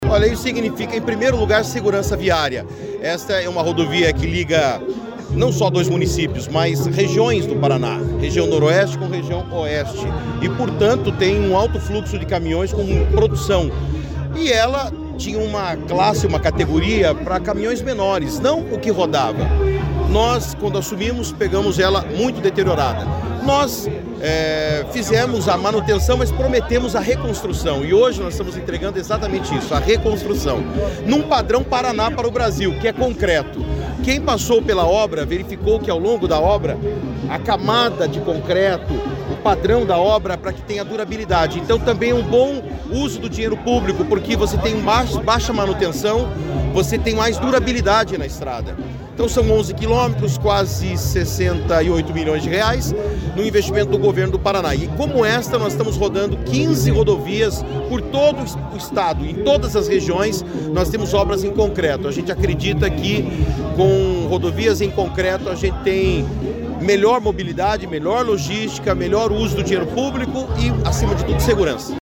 Sonora do secretário de Infraestrutura e Logística, Sandro Alex, sobre a pavimentação em concreto da PR-180